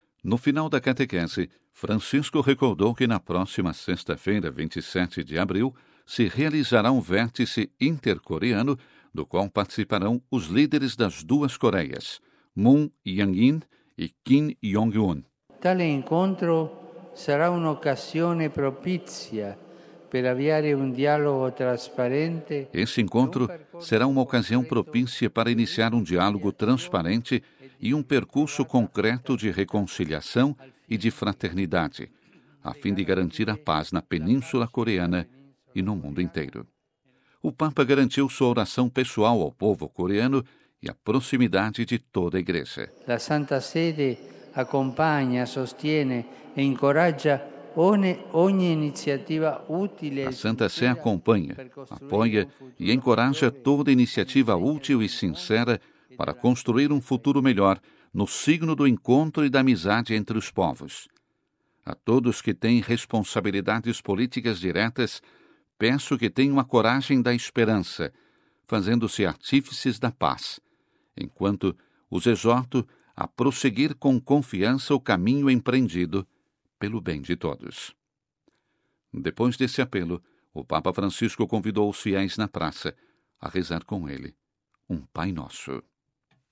O Papa Francisco rezou um Pai-Nosso com os fiéis na Praça São Pedro em vista do encontro entre os dois líderes coreanos na próxima sexta-feira.